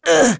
Neraxis-Vox_Damage_02.wav